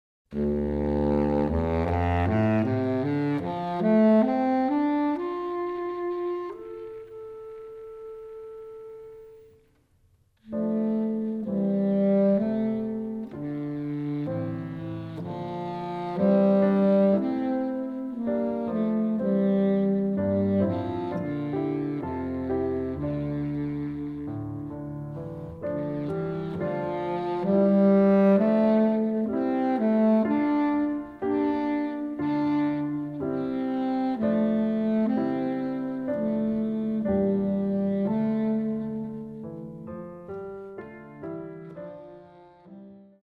baritone saxophone and piano